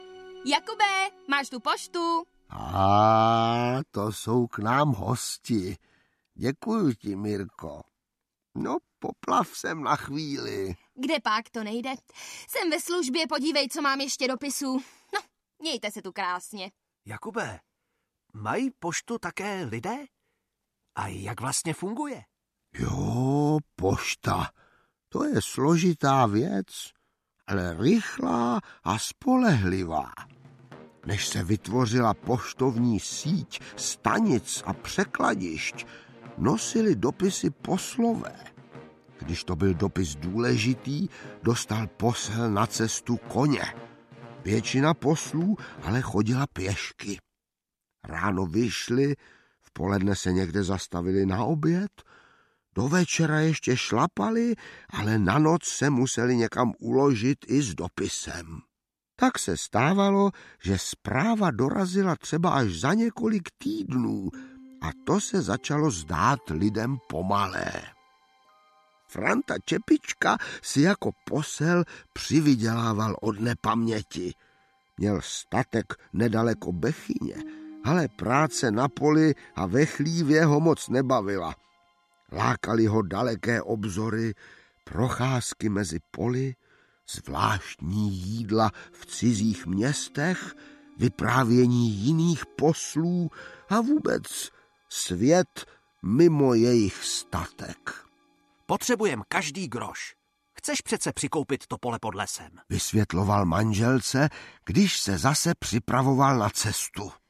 Pohádky z jižních Čech a Šumavy 3 aneb vyprávění kapra Jakuba audiokniha
Ukázka z knihy
Po dvou úspěšných sériích jihočeských pohádek z let 2011 a 2012 vychází už třetí pokračování, ve kterých nás vypravěč kapr Jakub zavede na různá zajímavá jihočeská místa. Celý projekt podpořil Jihočeský kraj, nahrávky vznikly v Českém rozhlase České Budějovice a objeví se i v programu Rádia Junior.